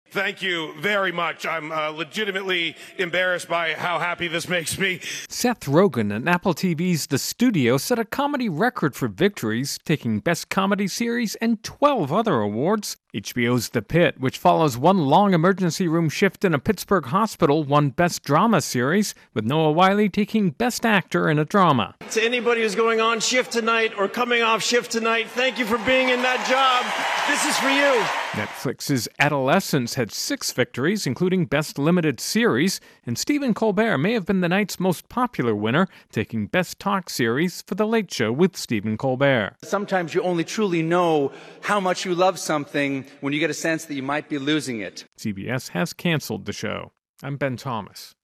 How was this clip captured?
((opens with actuality.))